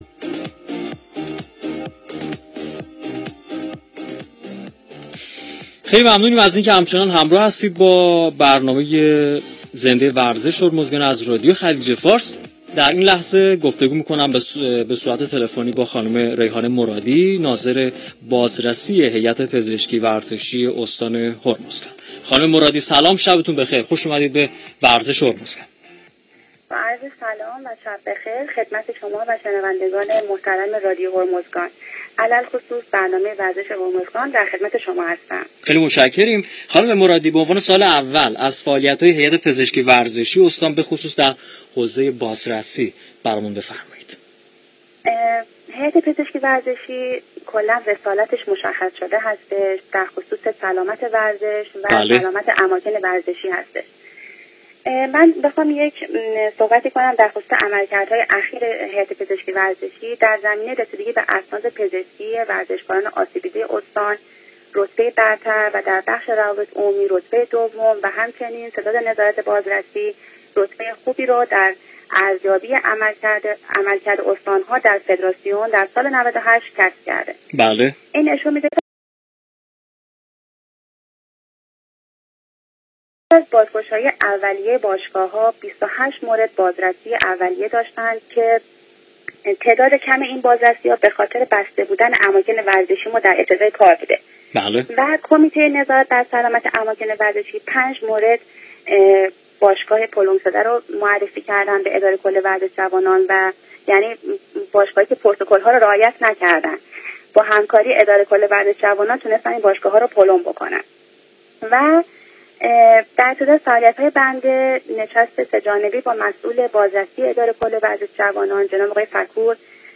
// گفت و گوی رادیویی //